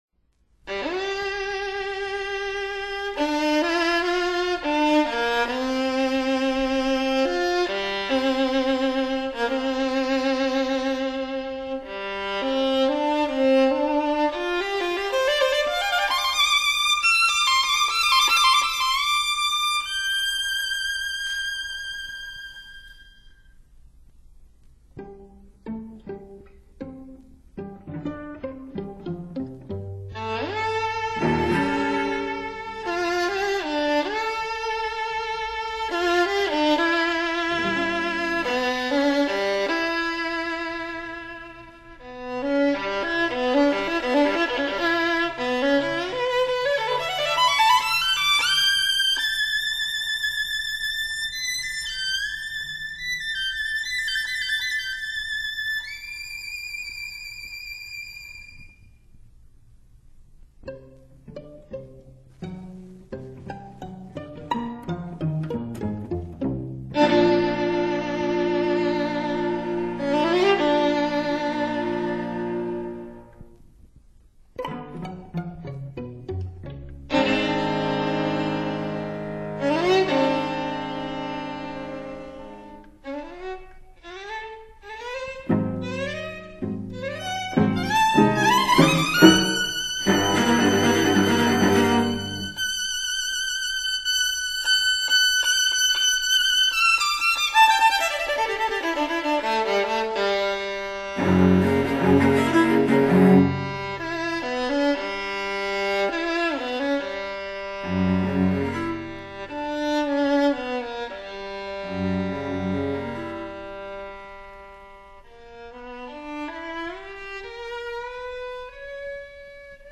名録音探訪ページで古いシュタルケルの独奏を収録したが、ここでは二重奏でも迫真の楽器の音が鳴るモノラル録音を味わっていただきたい。
ヤーノシュ・シュタルケル（チェロ）
Vn&Vc56.wma